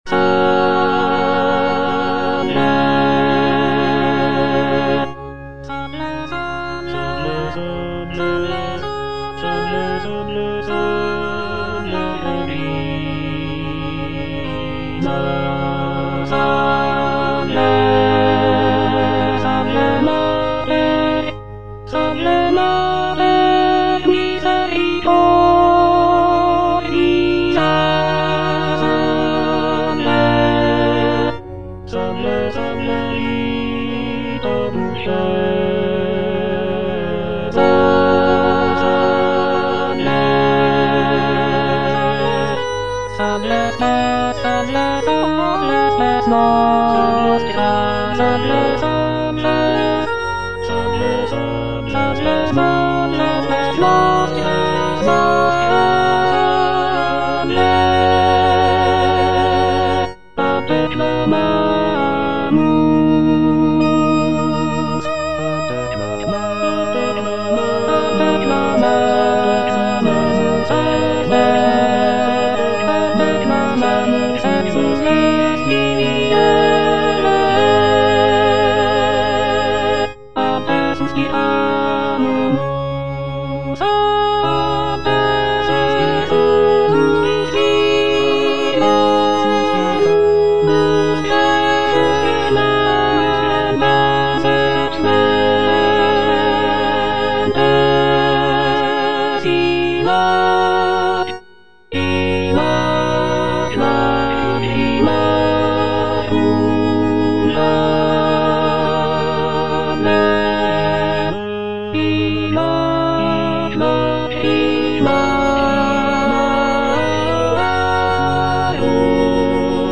G.F. SANCES - SALVE, REGINA (A = 415 Hz) Alto (Emphasised voice and other voices) Ads stop: auto-stop
"Salve, Regina (A = 415 Hz)" is a sacred choral work composed by Giovanni Felice Sances in the 17th century. This piece is a setting of the traditional Latin Marian hymn "Salve Regina" and is performed in a lower pitch of A = 415 Hz, which was common in the Baroque era.